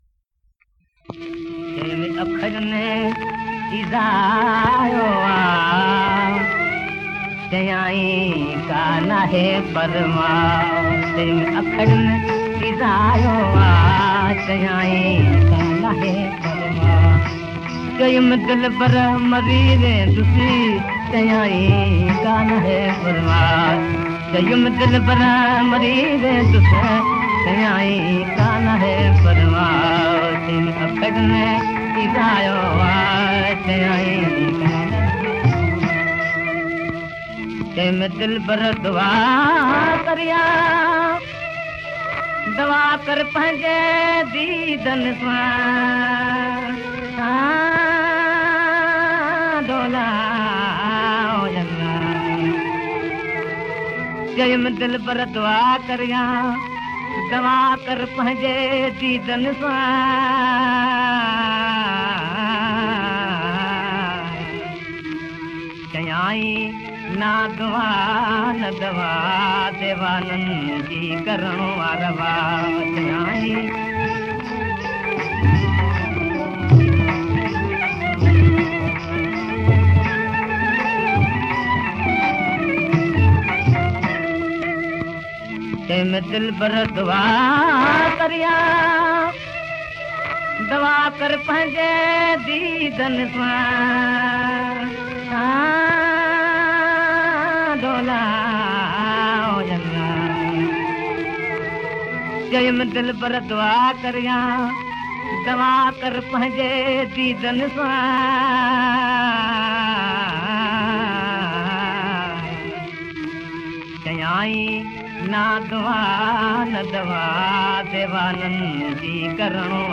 Converted from very old Gramophone records.